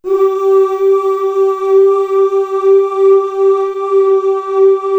Index of /90_sSampleCDs/Best Service ProSamples vol.55 - Retro Sampler [AKAI] 1CD/Partition C/CHOIR UHH